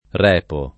repere [r$pere] v.; repo [